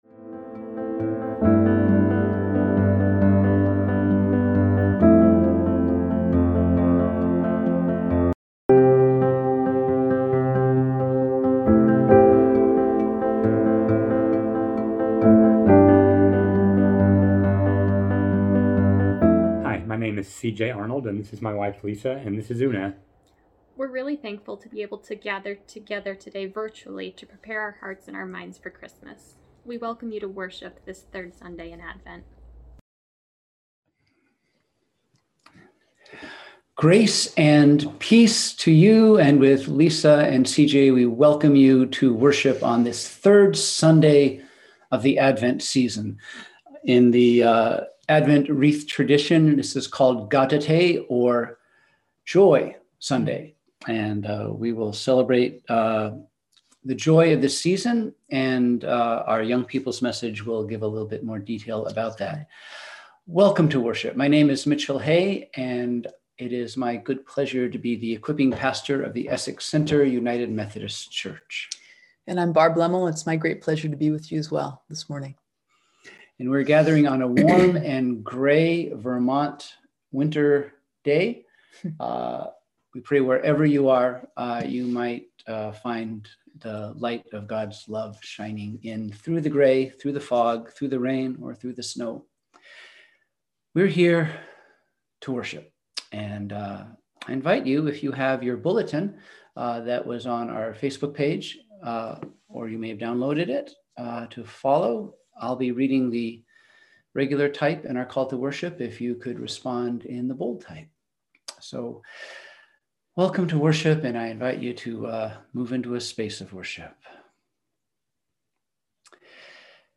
We held virtual worship on Sunday, December 13, 2020 at 10:00am.